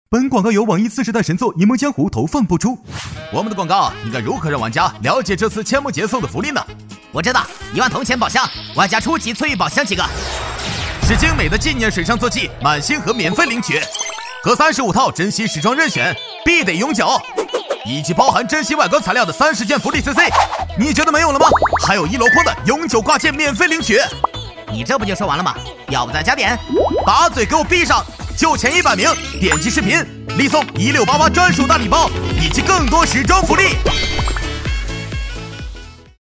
男C6-游戏广告-沙雕搞怪感觉
男C6-游戏广告-沙雕搞怪感觉.mp3